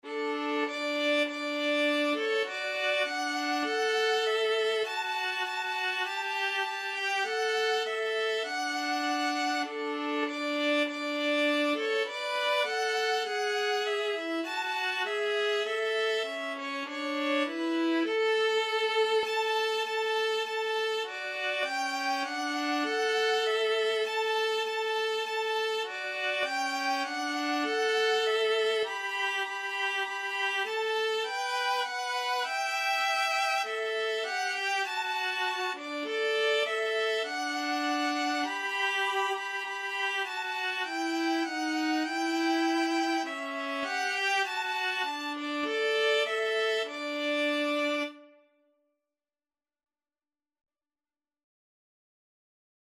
Free Sheet music for Violin-Viola Duet
D major (Sounding Pitch) (View more D major Music for Violin-Viola Duet )
4/4 (View more 4/4 Music)
Classical (View more Classical Violin-Viola Duet Music)